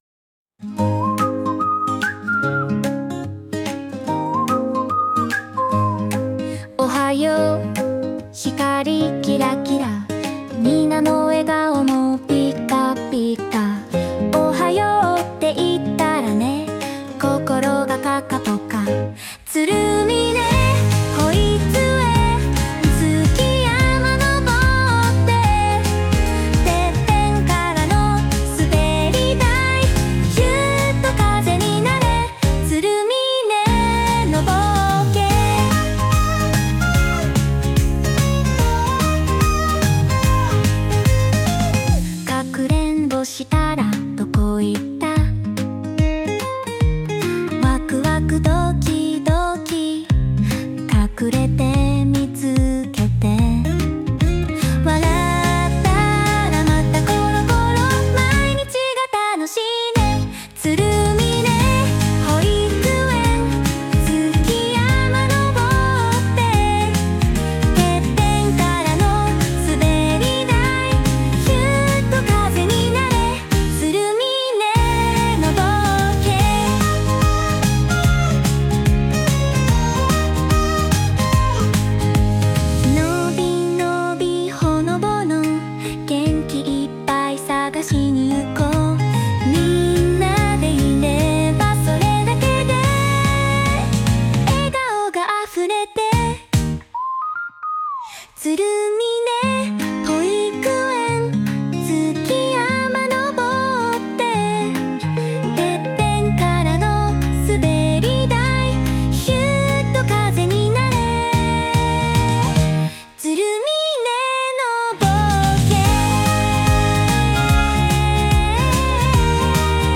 園歌 “つるみねの冒険“
AIを駆使して、つるみね保育園の歌を創りました！